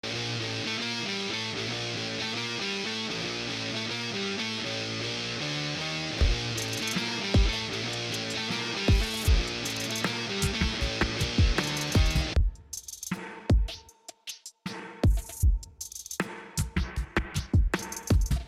mp3,434k] Рок